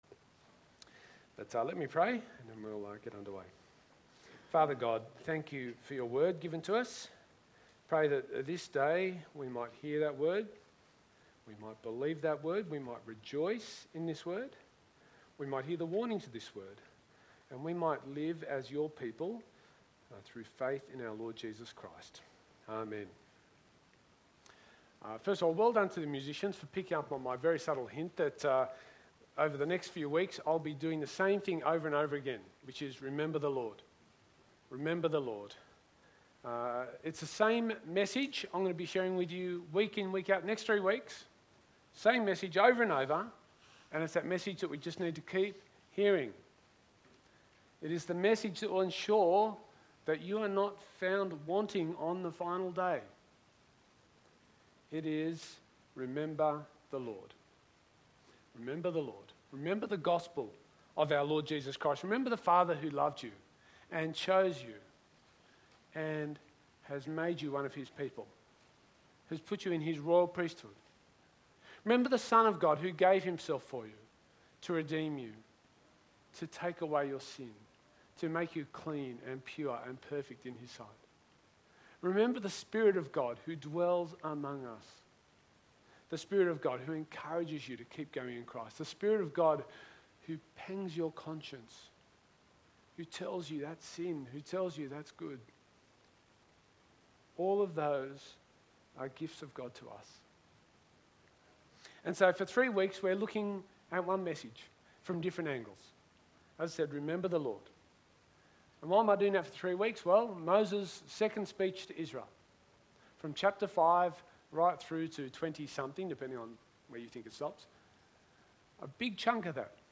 SERMON – Remember the Lord